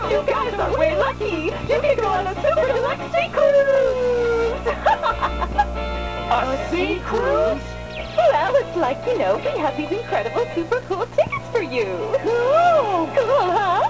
S.S. ANNE TICKET CONVERSATION WITH TEAM ROCKET